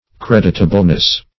\Cred"it*a*ble*ness\